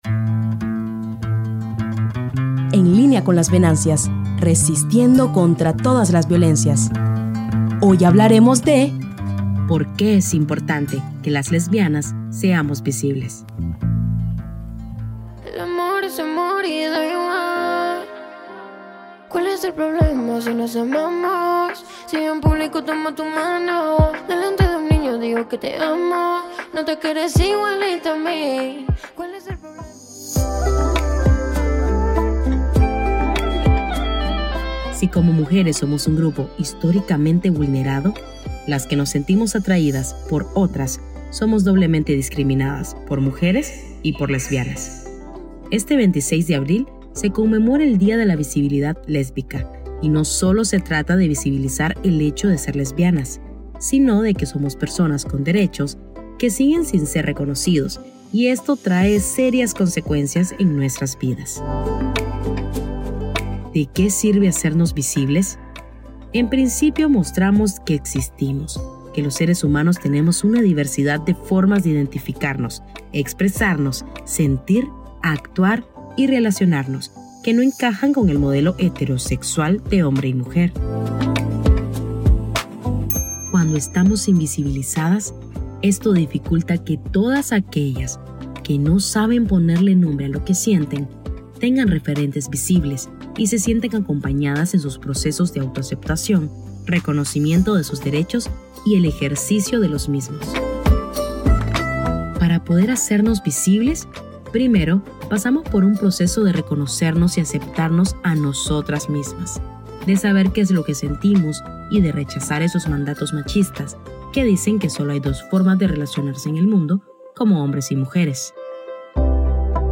Musicalización y recursos sonoros:
Música y efectos de la biblioteca de sonidos de uso libre de Meta: